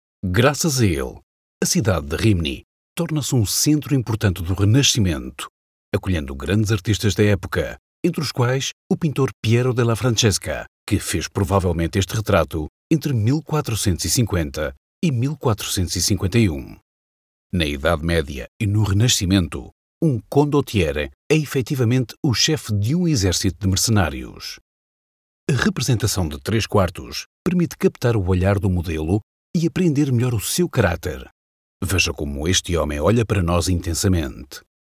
Natuurlijk, Speels, Vriendelijk, Warm, Zakelijk
Audiogids